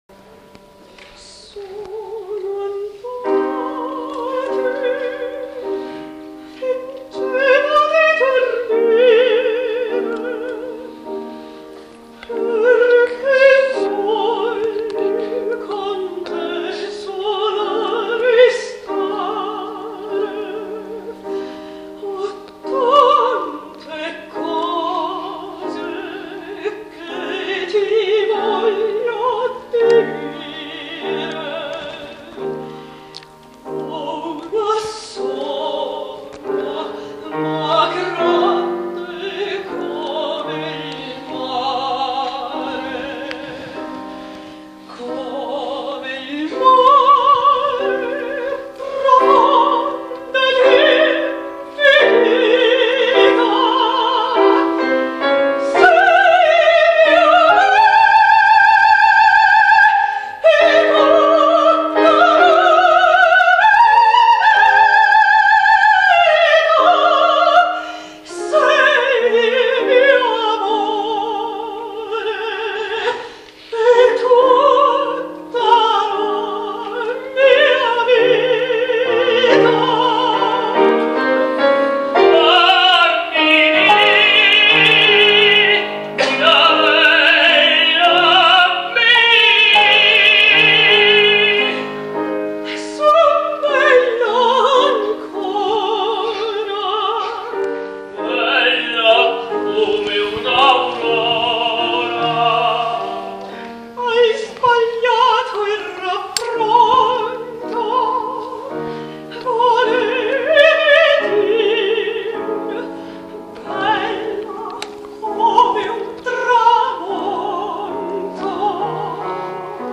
Cinema Teatro Odeon Vigevano